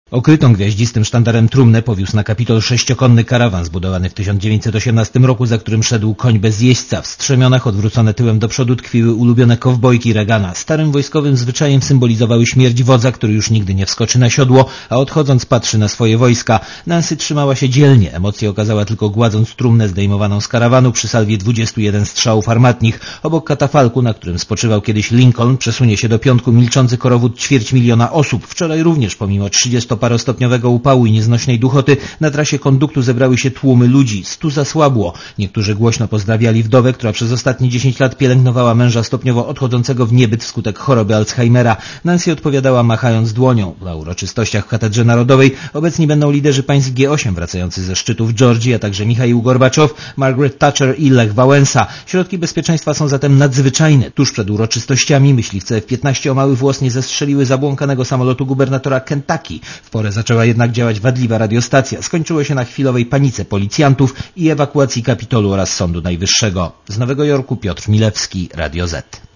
Posłuchaj relacji nowojorskiego korespondenta Radia ZET